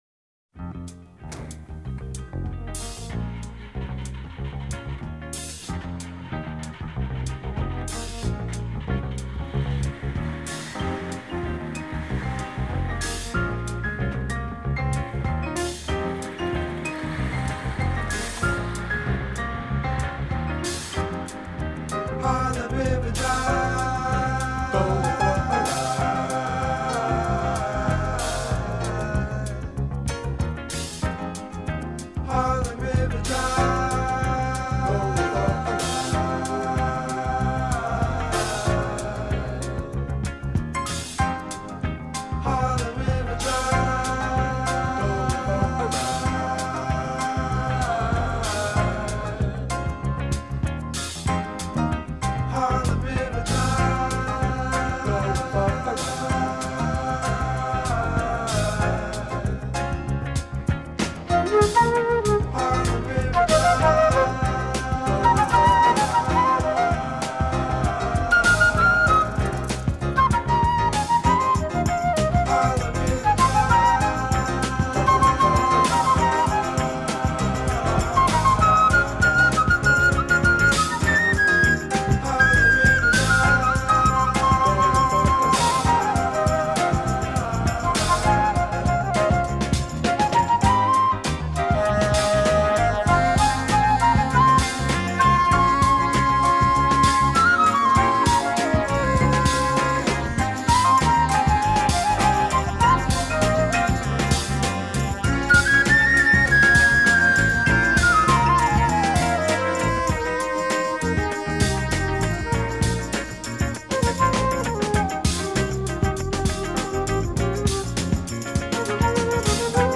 TEMPO: 101
Morceau en Fa mineur (gamme de Re Lab bemol, 4 bemoles)
Solo Flute